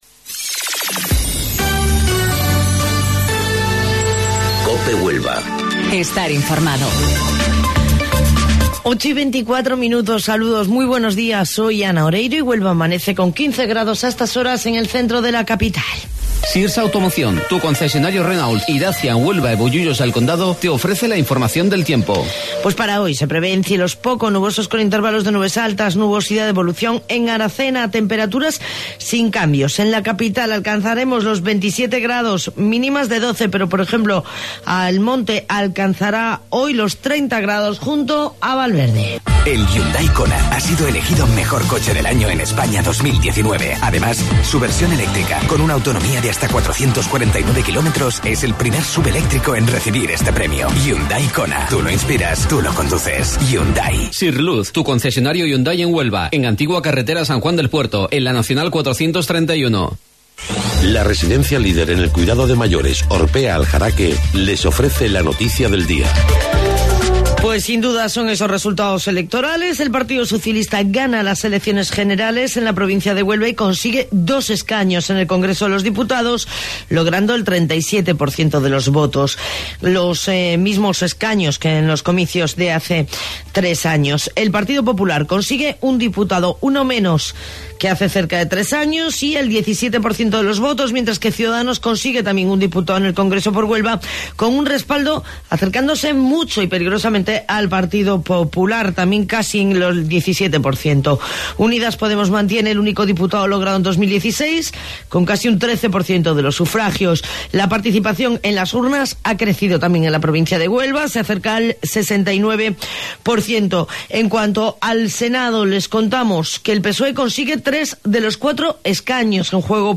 AUDIO: Informativo Local 08:25 del 29 de Abril